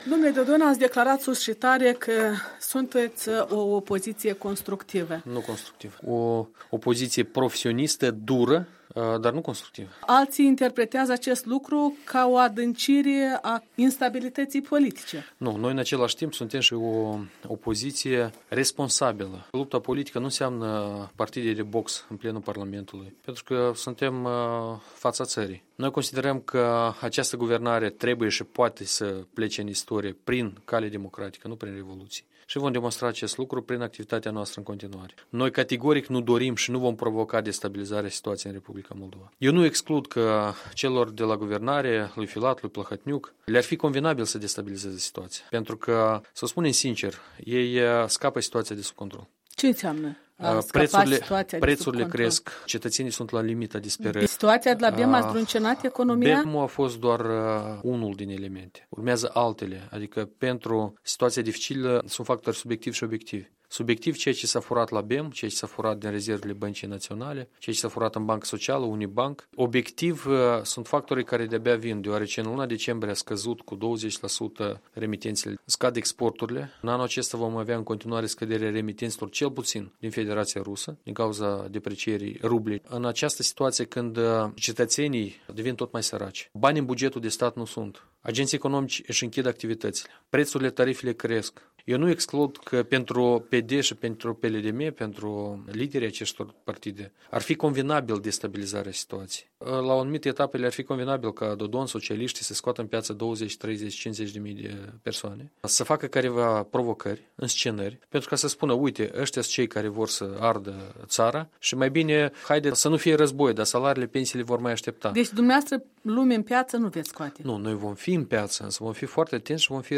Un interviu în exclusivitate cu liderul PSRM